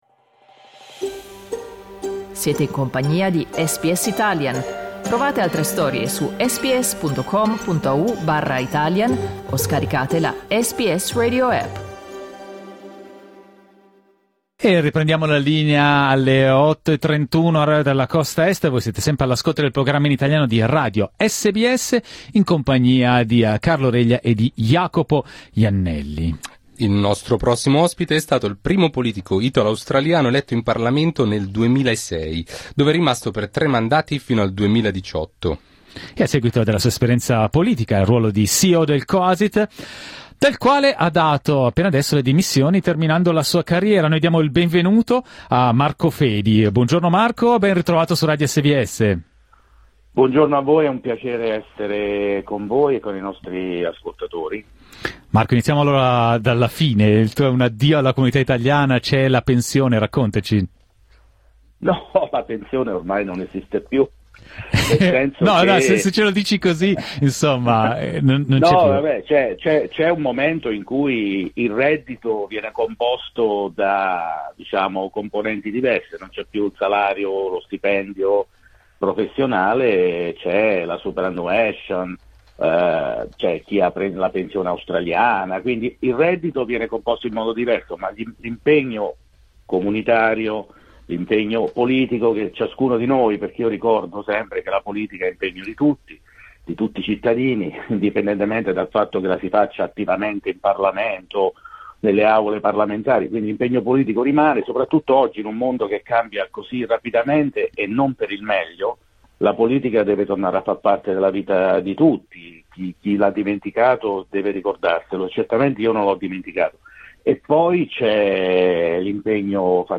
Nato ad Ascoli Piceno, Marco Fedi è arrivato in Australia nei primi anni '80, "seguendo il cuore", come ha raccontato in diretta a SBS Italian.